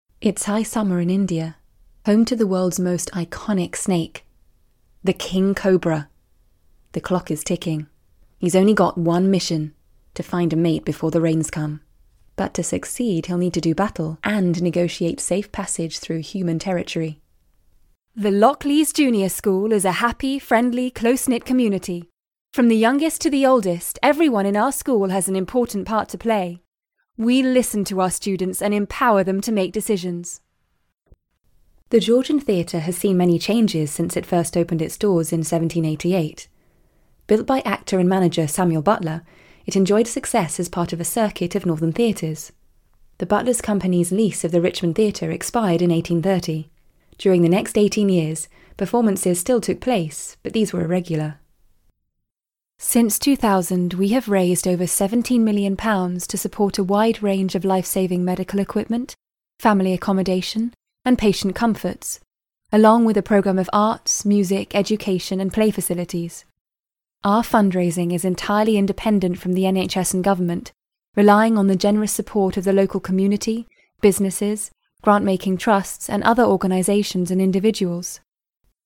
• Native Accent: Bristol, RP, Somerset, West Country
• Home Studio